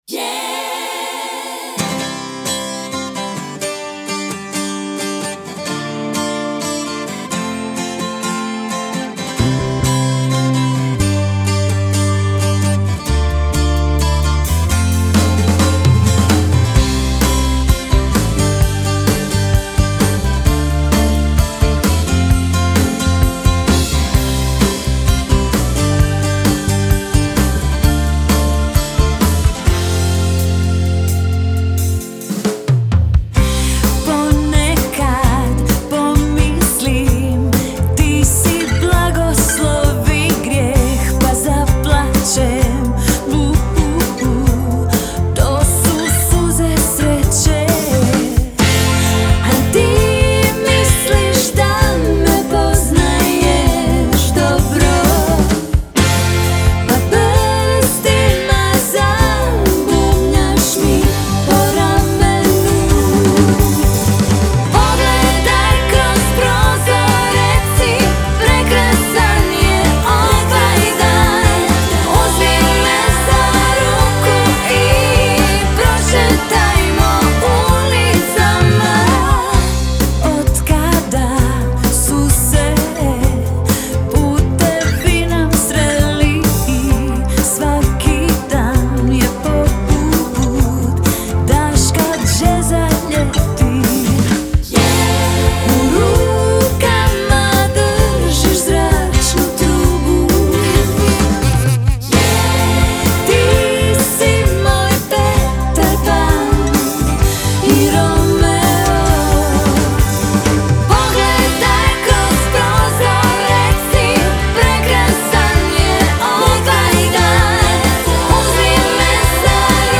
klaviaturah
bobnar